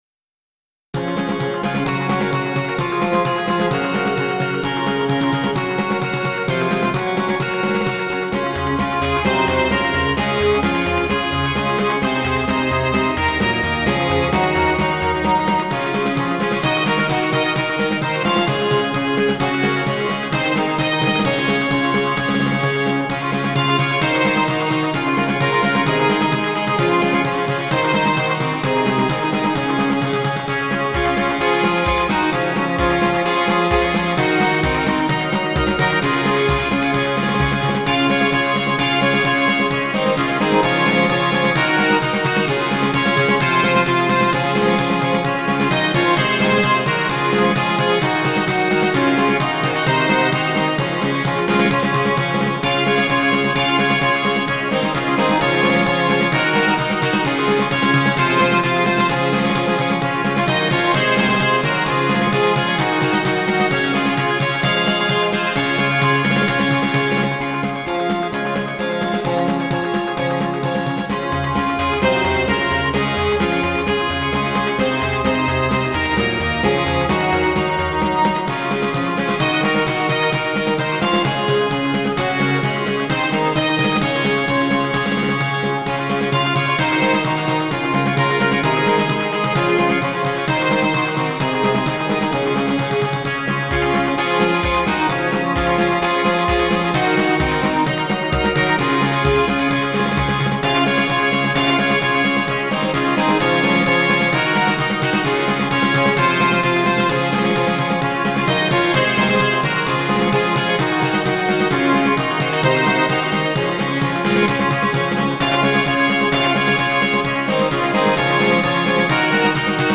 嬰ハ長調